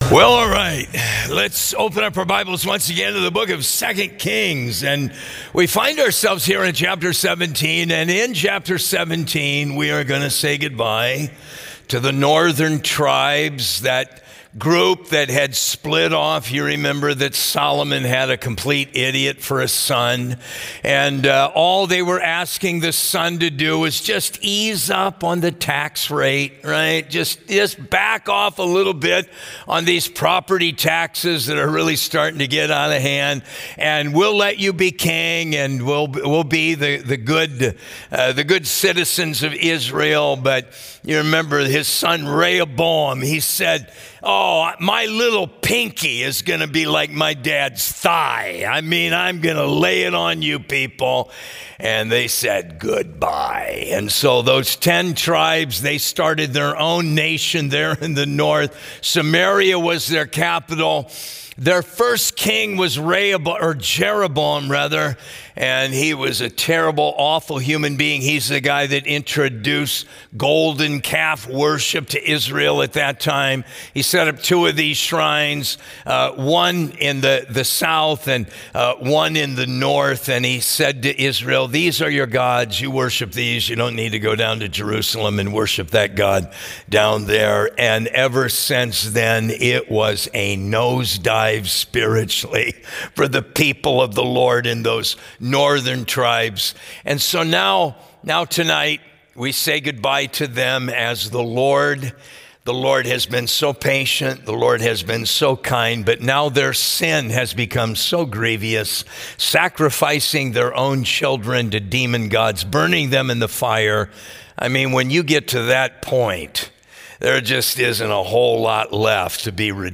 A study in 2 Kings 17 from our Midweek Service